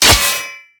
metal3.ogg